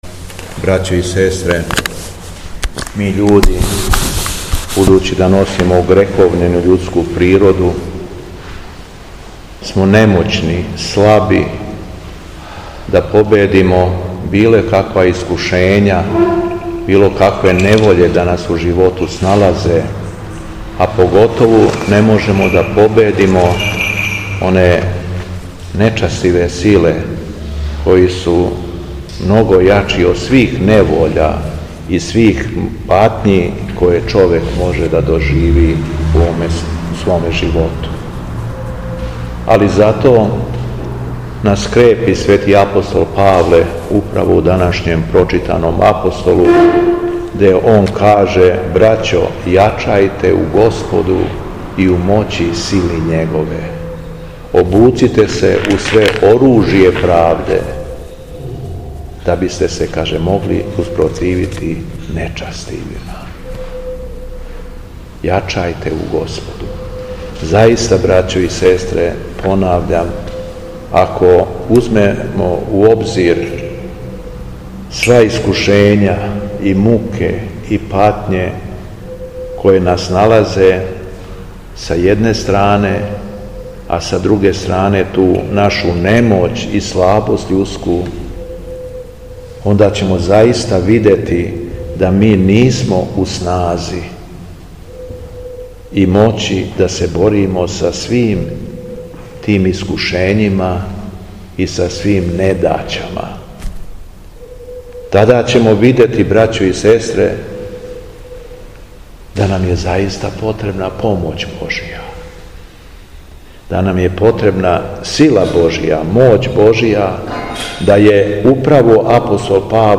ЕВХАРИСТИЈСКО САБРАЊЕ У КРАГУЈЕВАЧКОМ НАСЕЉУ БРЕСНИЦА У ХРАМУ СВЕТОГ ЈОАНИКИЈА ДЕВИЧКОГ - Епархија Шумадијска
Беседа Његовог Високопреосвештенства Митрополита шумадијског г. Јована
Митрополит се обратио сакупљеном народу након прочитаног Јеванђеља: